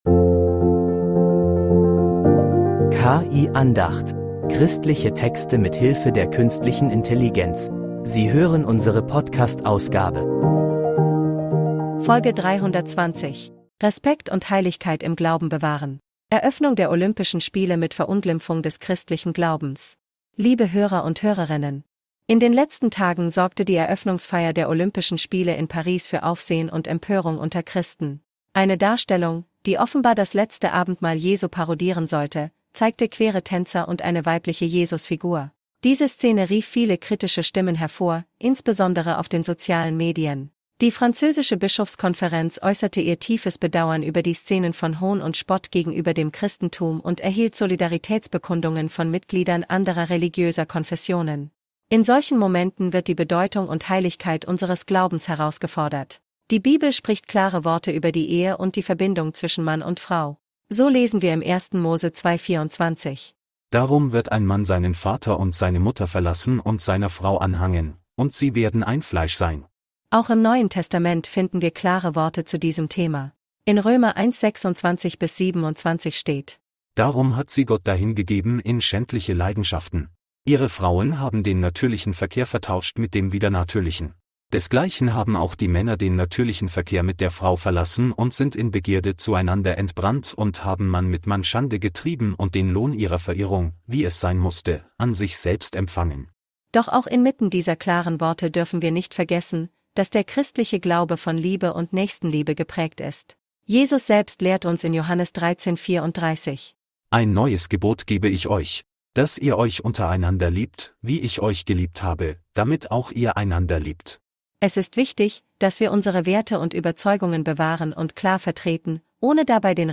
Diese Predigt betont die Wichtigkeit